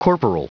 Prononciation du mot corporal en anglais (fichier audio)
Prononciation du mot : corporal